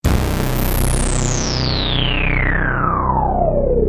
Sequencial Circuits - Prophet 600 55